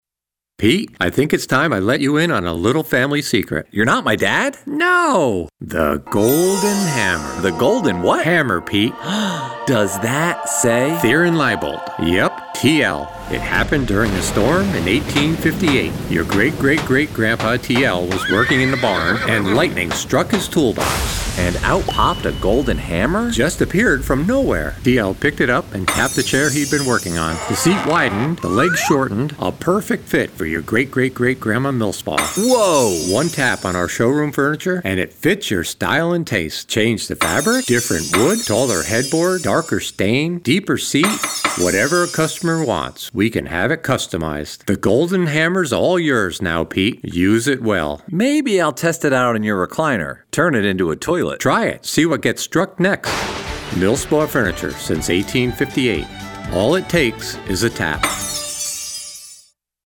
Radio Ads